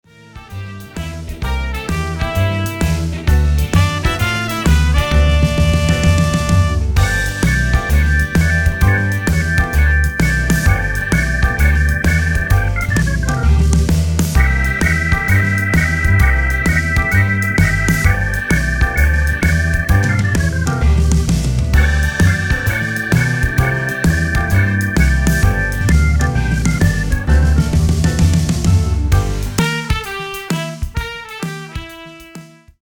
130 BPM